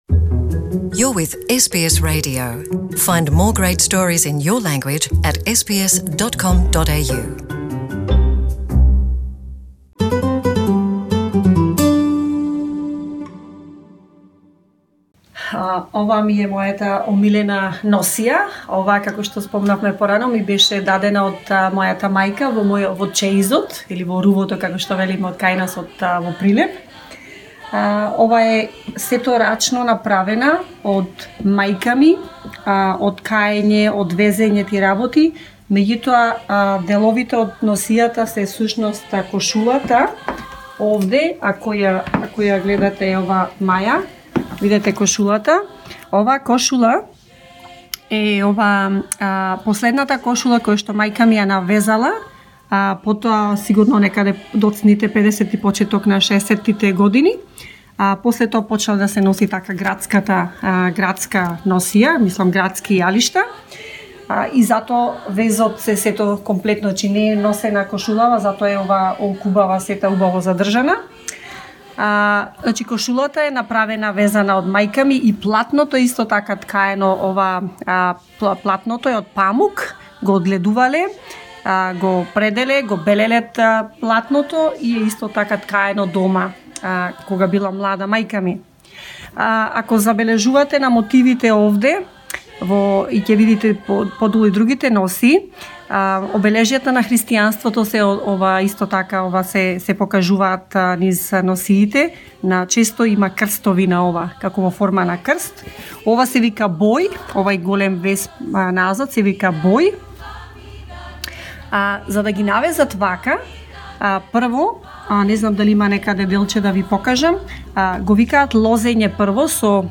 In the second part of the interview with SBS Macedonian, she discusses the Macedonian traditional costume that holds the most prominent place in her collection.